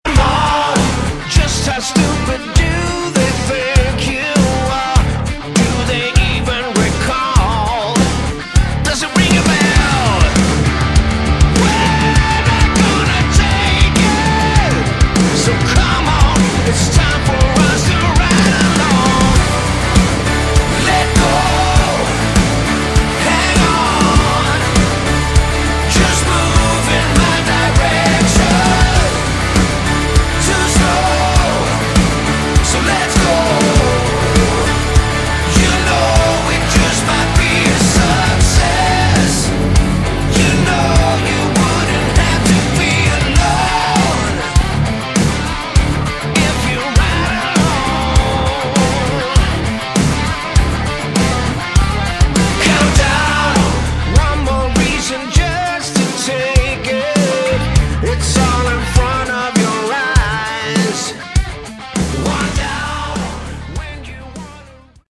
Category: Melodic Rock
bass, vocals
drums
keyboards
guitars